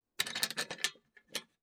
Part_Assembly_45.wav